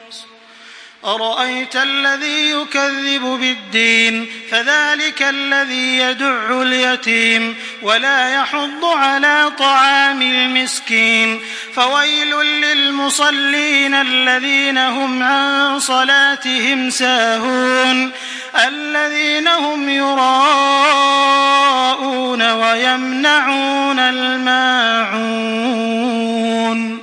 Makkah Taraweeh 1425
Murattal